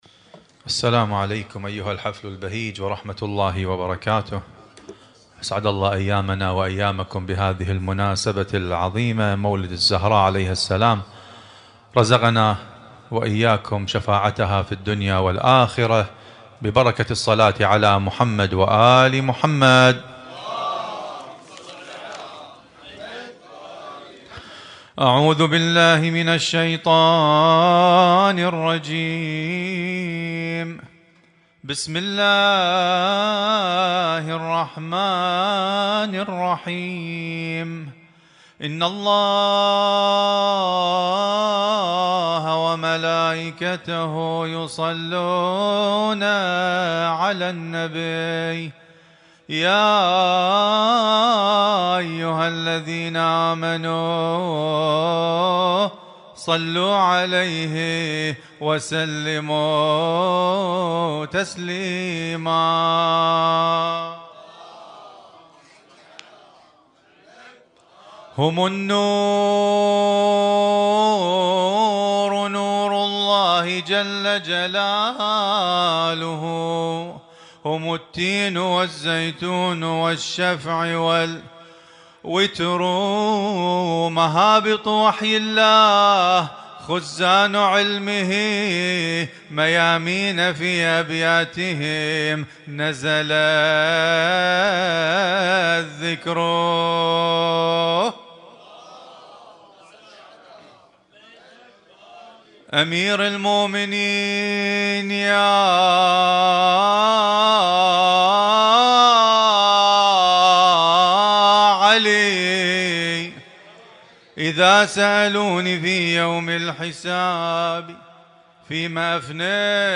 Husainyt Alnoor Rumaithiya Kuwait
اسم النشيد:: مولد فاطمة الزهراء عليها السلام